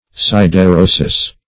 Siderosis \Sid`e*ro"sis\, n.[NL., fr. Gr. ??? iron.] (Med.)